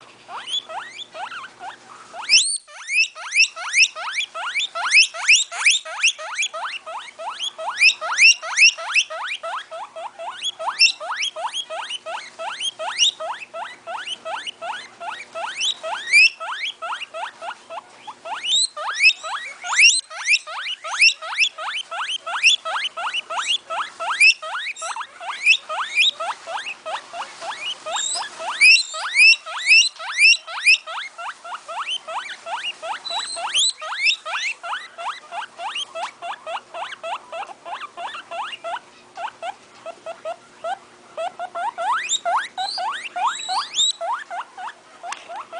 cris-cochon-dinde.mp3